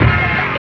23 HIT 2.wav